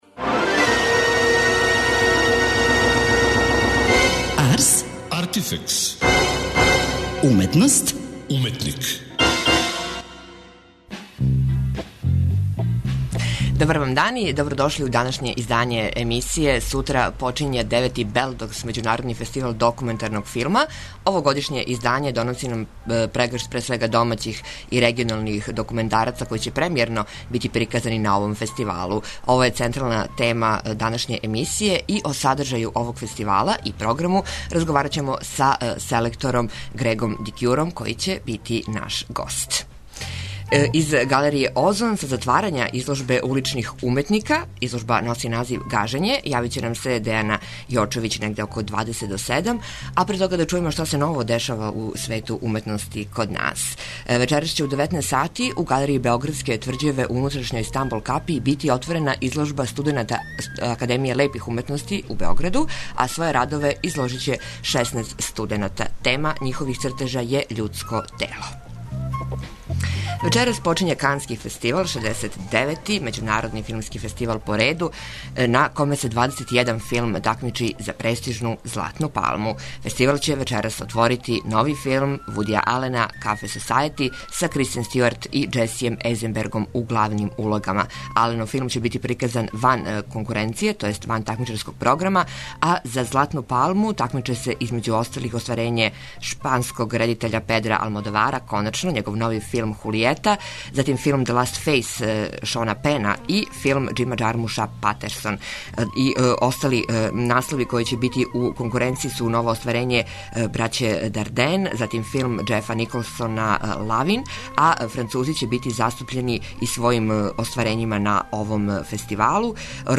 Из галерије ОЗОН са затварања изложбе уличних уметника "Гажење" јавиће нам се наш репортер.